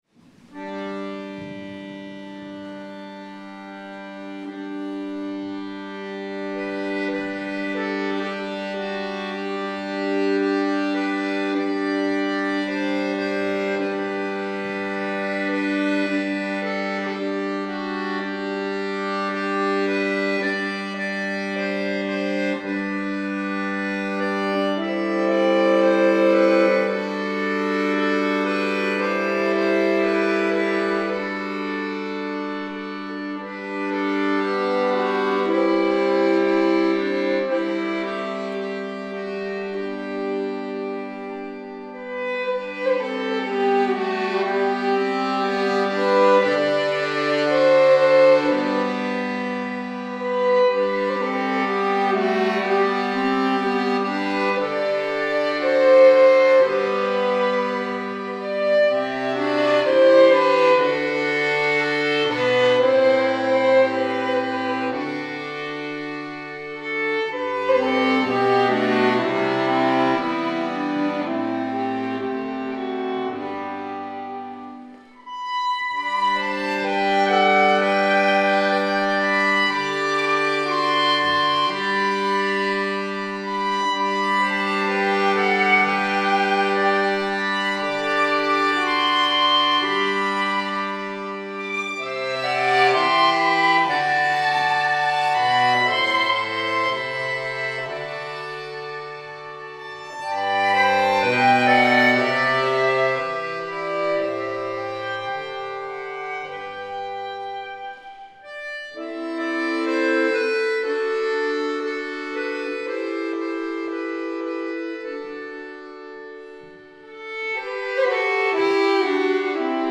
Jeg er klassisk violinist, og kan levere underholdnings- receptions- dinérmusik til din fødselsdag, firmafest, bryllup, i festlokale, forsamlingshus, dit hjem, slot, kirke, have, kontor mv.
• Nu blomstertiden kommer (svensk folkemelodi)